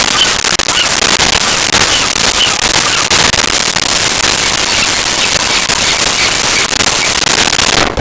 Ocean Waves And Birds
Ocean Waves And Birds.wav